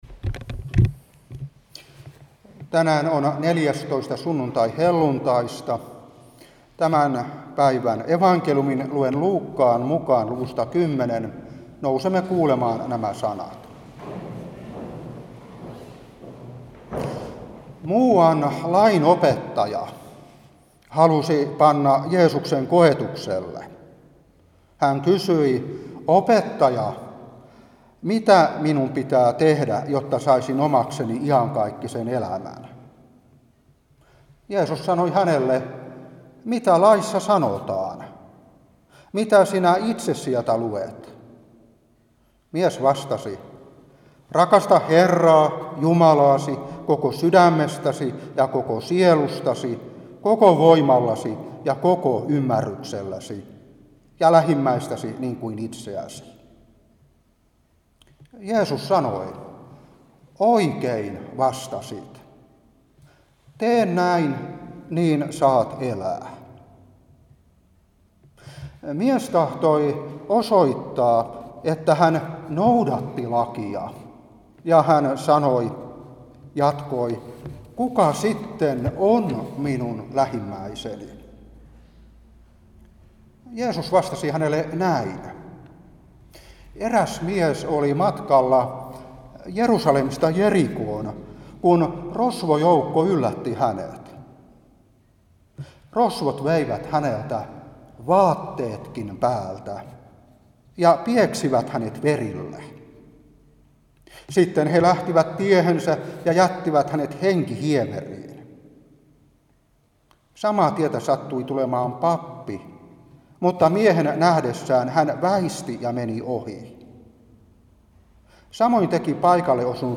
Saarna 2022-9.